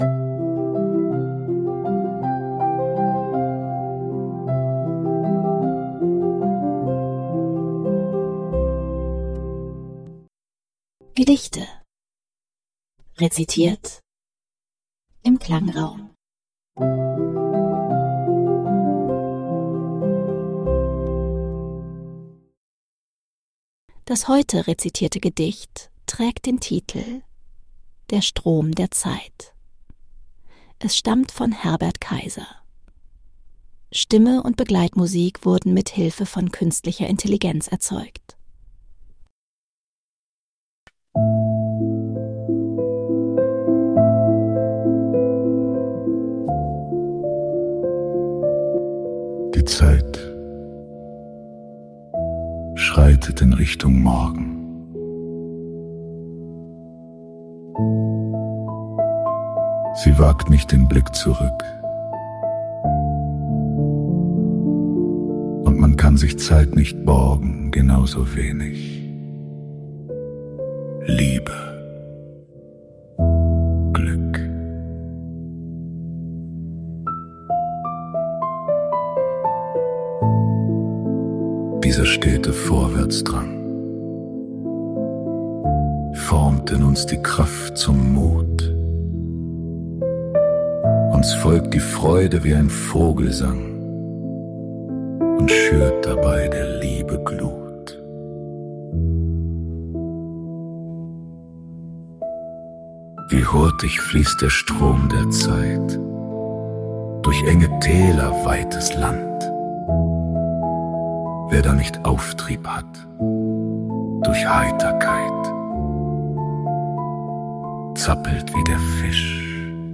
Stimme und Begleitmusik wurden mithilfe
Künstlicher Intelligenz erzeugt.